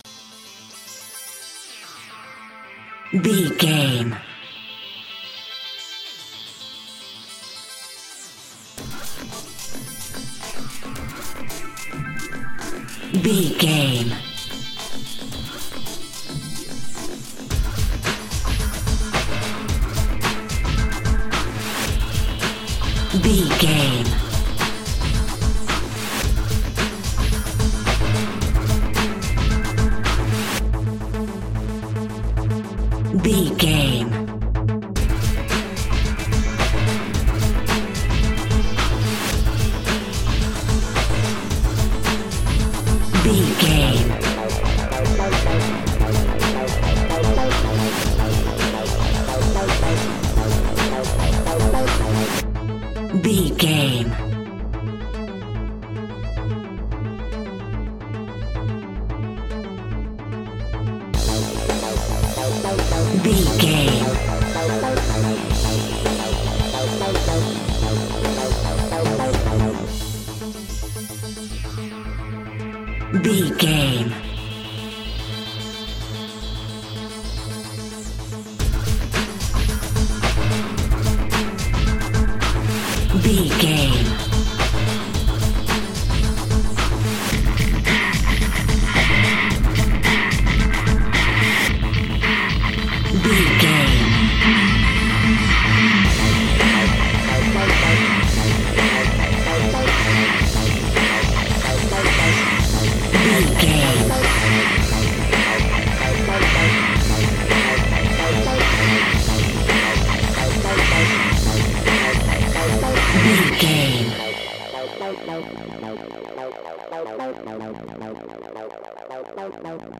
Atmospheres and Electrics.
Fast paced
Aeolian/Minor
aggressive
dark
energetic
driving
futuristic
drum machine
synthesiser
techno
trance
industrial
glitch
synth lead
synth bass